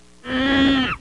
Chimp Screech (single) Sound Effect
Download a high-quality chimp screech (single) sound effect.
chimp-screech-single.mp3